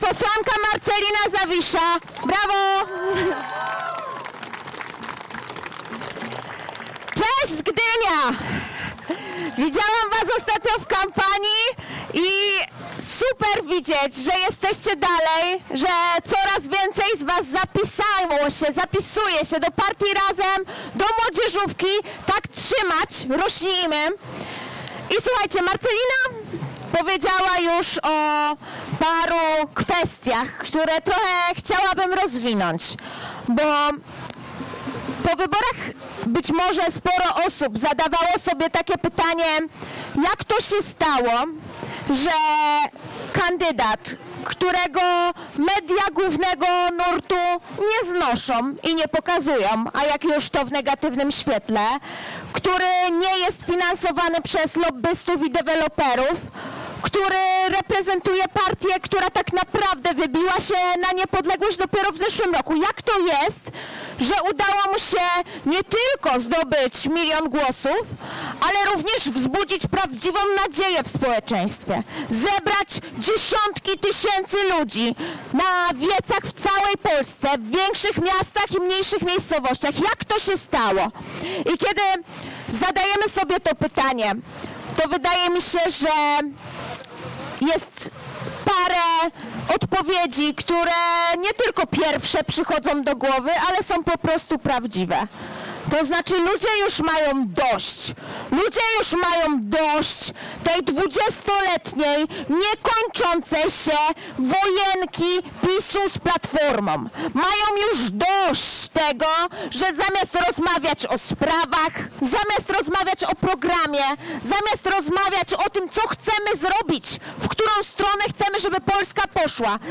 W wieczornym wiecu organizowanym w ramach trwającej trasy Lato z Razem